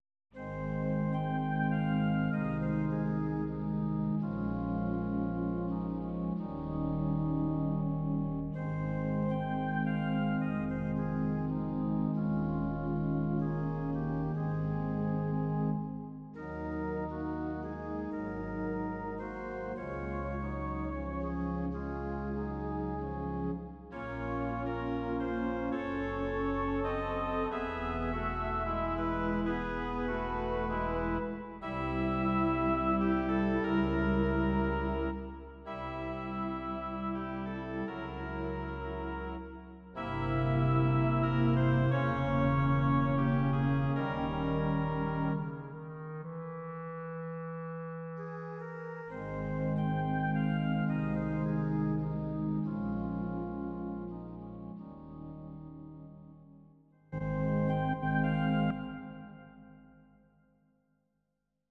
These are MP3 versions of our MIDI file catalogue.
Your-Mix: Instrumental (2074)